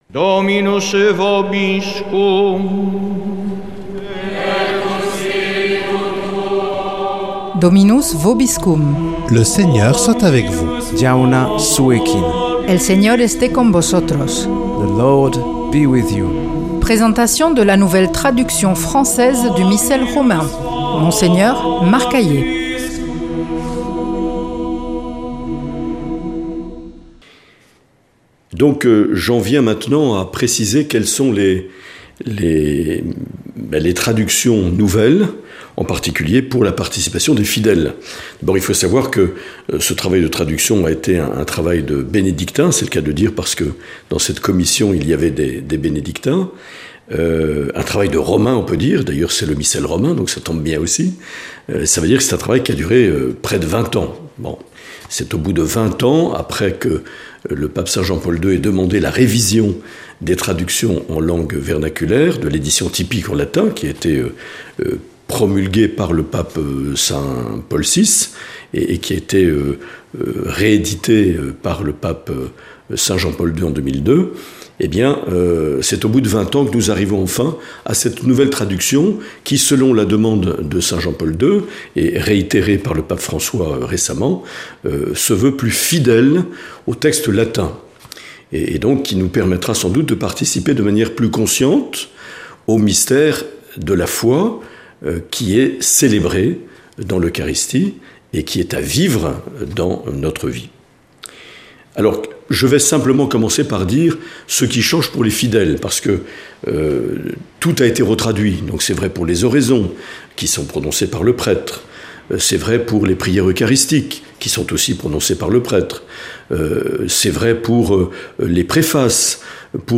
Présentation de la nouvelle traduction française du Missel Romain par Mgr Marc Aillet
Monseigneur Marc Aillet